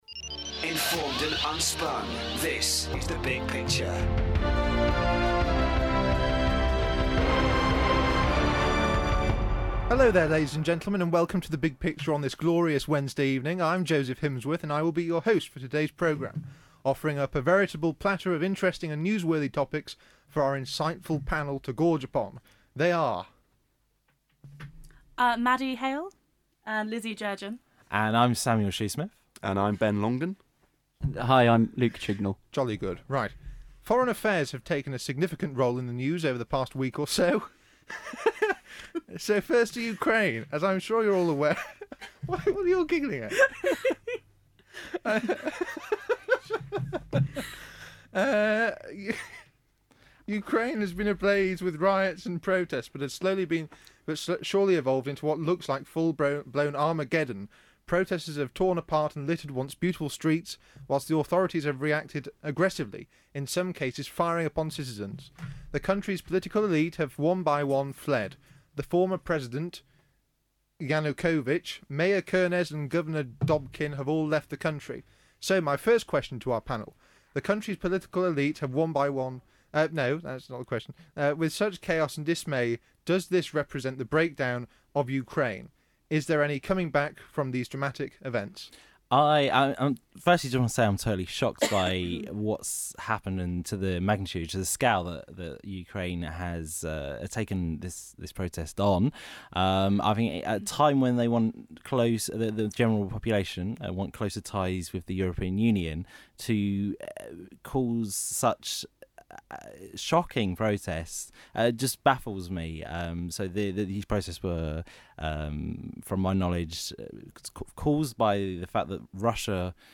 The panel discusses the growing unrest in Ukraine, the Daily Mail's campaign against Harriet Harman over her historic links through the National Council for Civil Liberties with the Paedophilia Information Exchange; and the coming showdown between Nick Clegg and Nigel Farage over Britain's EU membership. 42:08 minutes (48.22 MB) big picture daily mail eu harriet harman nick clegg nigel farage paedophilia smear ukraine Yes, include in podcast listings 1 comment Download audio file